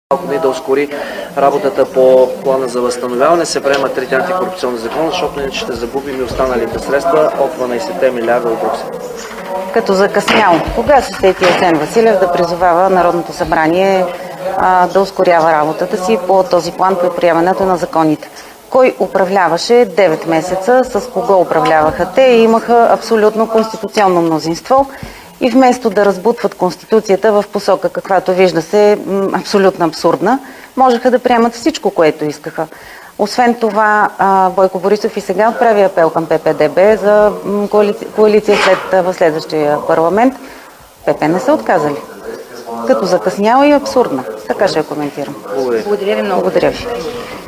11.10 - Брифинг на председателя на ДПС Делян Пеевски. - директно от мястото на събитието (Народното събрание)